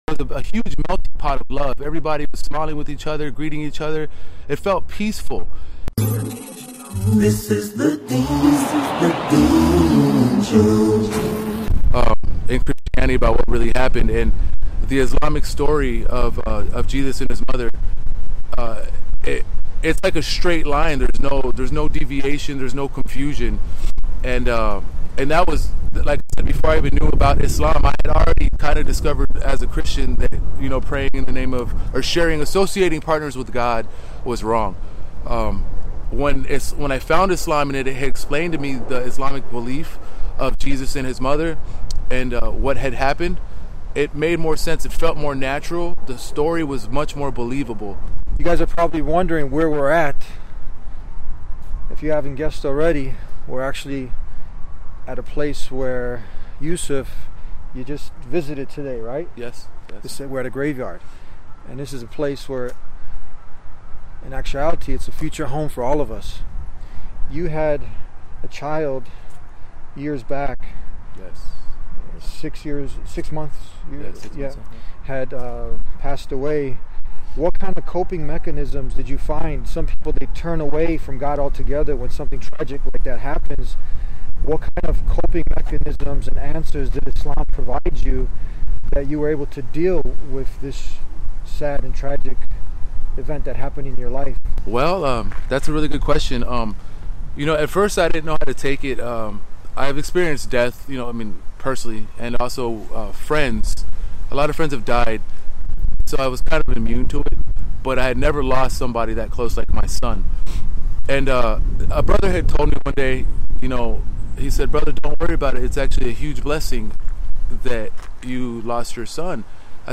walk-and-talk